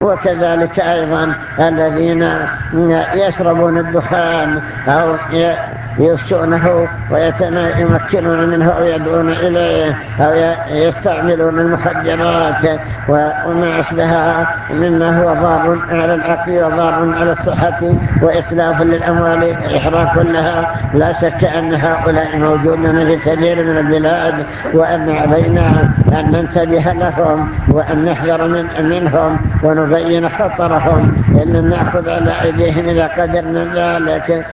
المكتبة الصوتية  تسجيلات - محاضرات ودروس  محاضرة بعنوان من يرد الله به خيرا يفقهه في الدين التحذير من بعض المنكرات